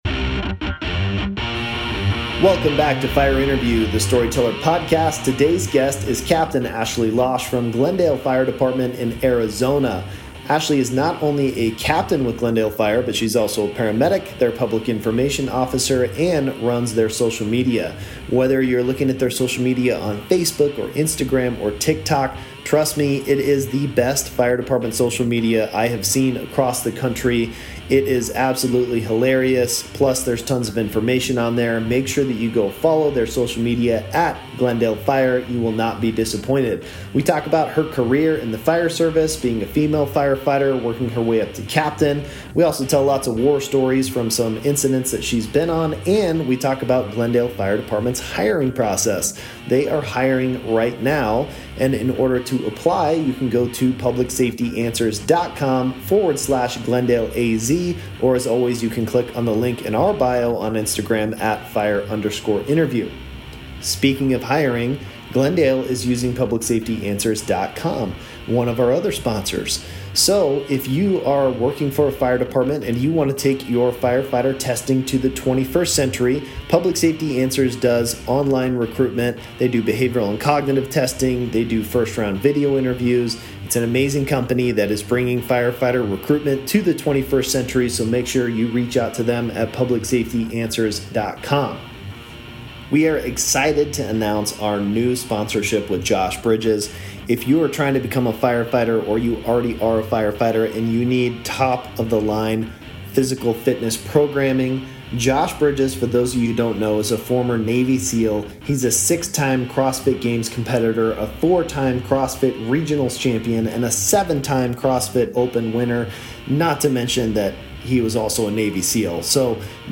Fire Interview